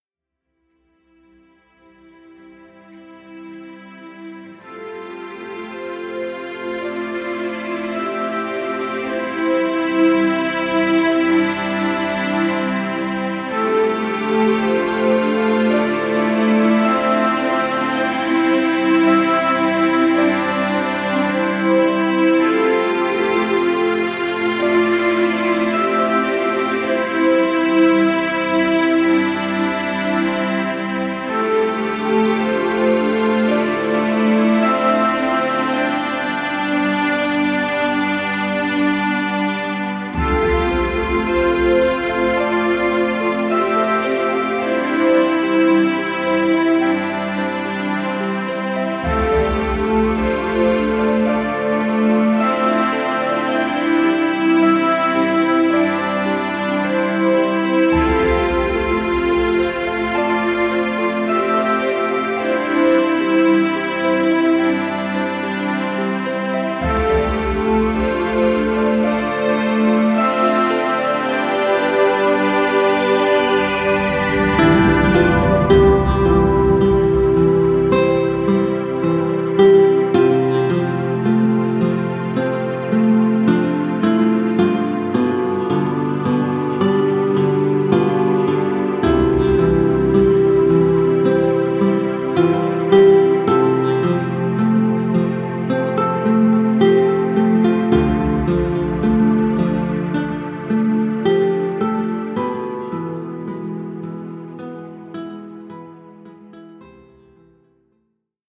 Natürlich auch als Einschlafhilfe bestens geeignet.